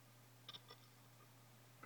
Nach dem aufspielen der Daten habe ich festgestellt, dass die Platte Geräusche macht.
Dieses Zirpen hört sich sehr komisch an und mein Mac stürtzt auch häufig nach dem aufwachen aus dem Ruhezustand ab.
intenso-geraeusch.AIF